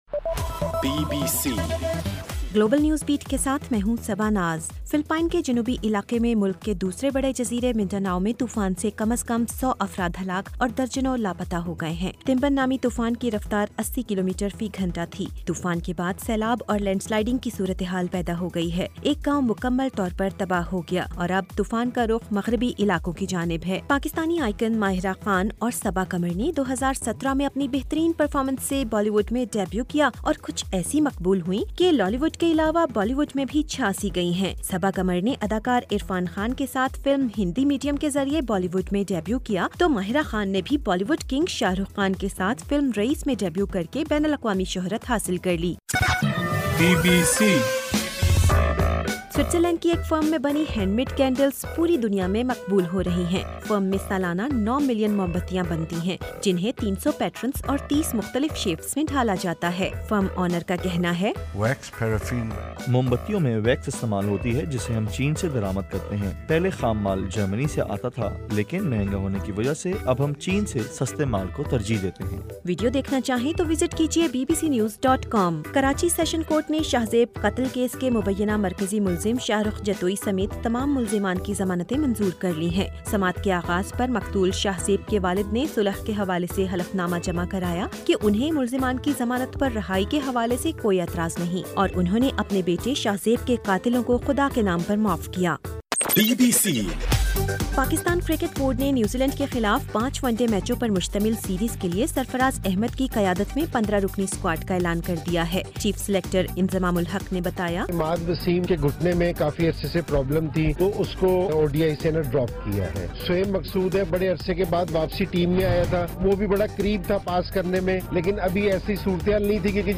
گلوبل نیوز بیٹ بُلیٹن اُردو زبان میں رات 8 بجے سے صبح 1 بجے تک ہر گھنٹےکے بعد اپنا اور آواز ایفایم ریڈیو سٹیشن کے علاوہ ٹوئٹر، فیس بُک اور آڈیو بوم پر ضرور سنیے